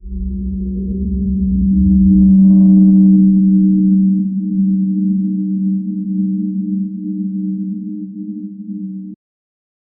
A faint, otherworldly humming begins under water.
a-faint-otherworldly-humm-y3lbvfum.wav